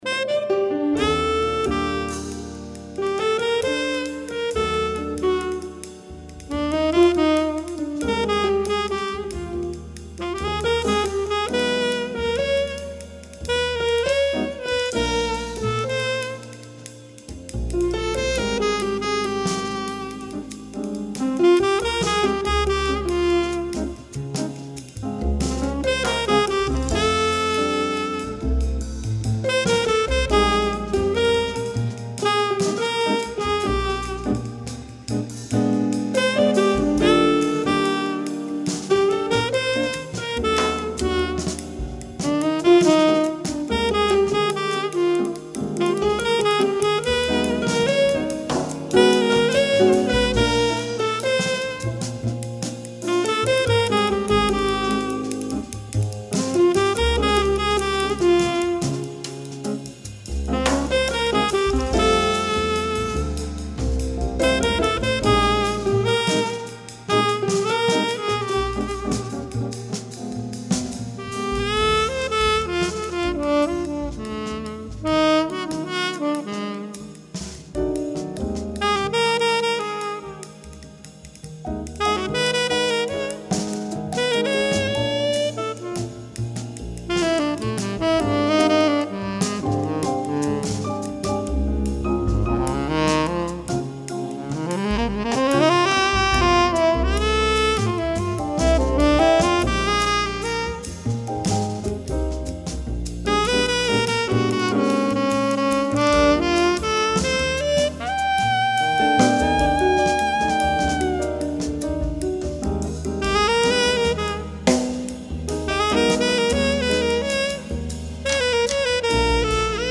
sax tenore
Piano
Basso
Batteria